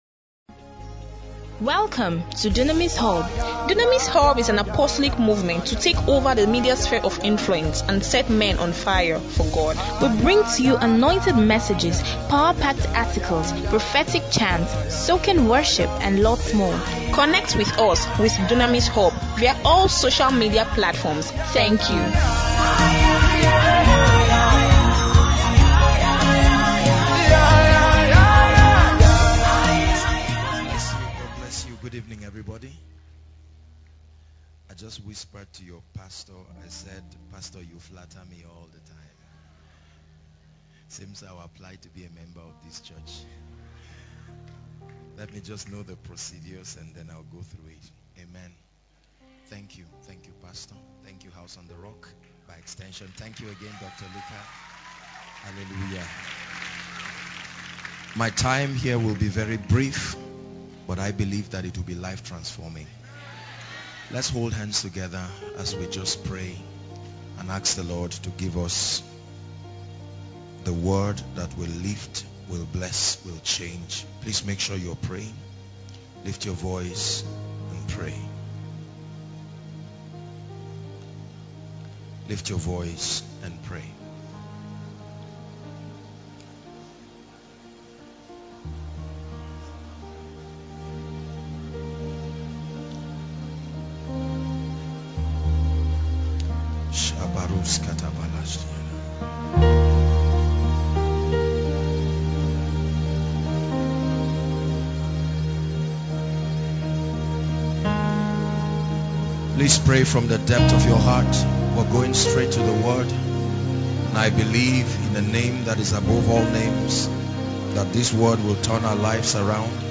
The Secret Place In this sermon
during the weekly meeting organize by Eternity Network International (ENI) tagged Koinonia. A meeting where Christians encounter a deep intimacy with the Holy Spirit.